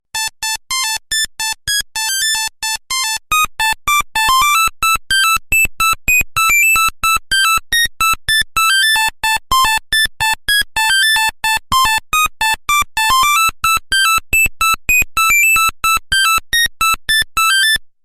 16. Мелодия старого сотового телефона